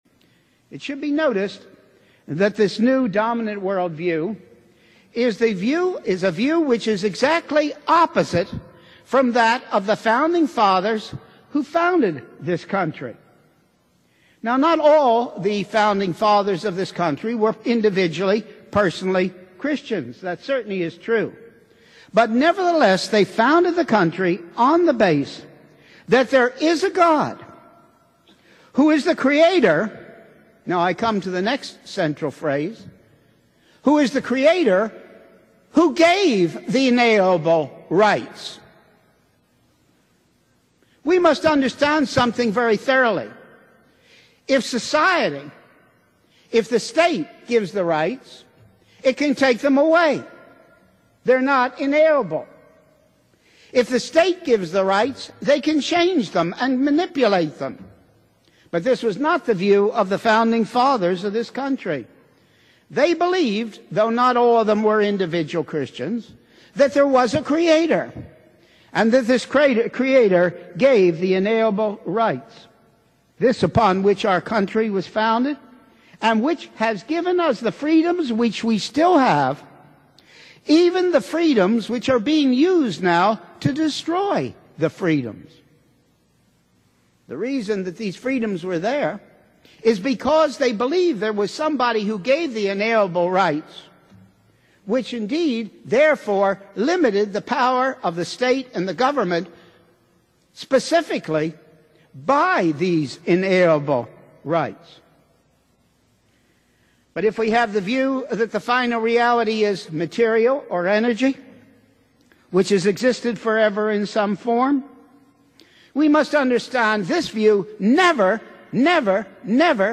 Just a couple of years before he died, Dr. Schaeffer spoke at the Coral Ridge Presbyterian Church in Fort Lauderdale, Florida. He presented the heart of the content of his bestselling book A Christian Manifesto.